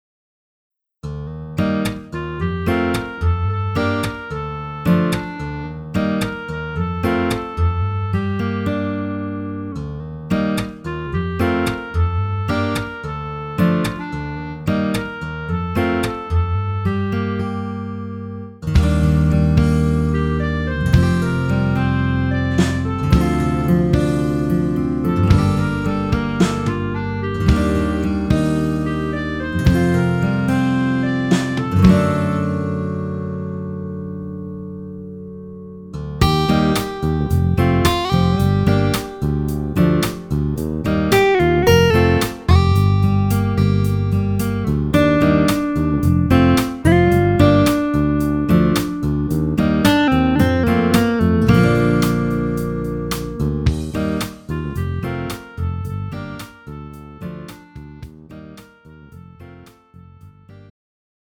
음정 원키 3:23
장르 가요 구분 Pro MR